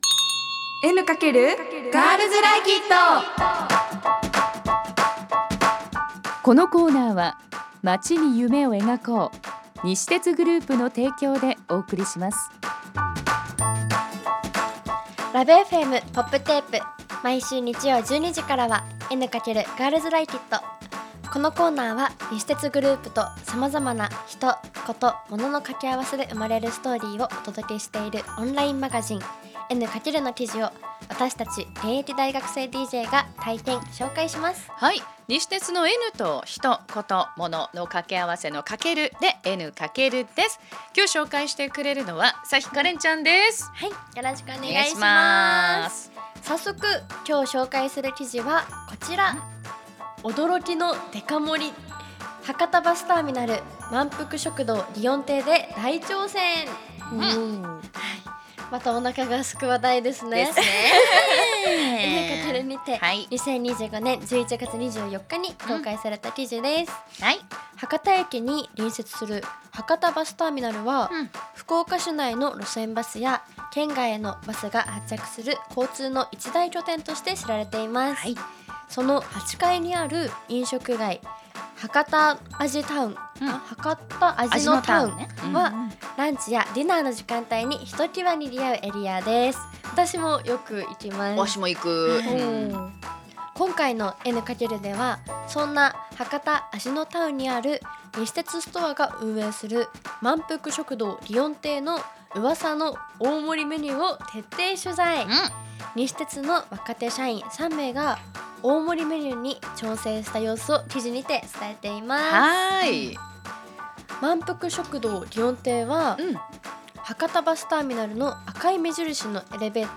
女子大生DJが「N× エヌカケル」から気になる話題をピックアップ！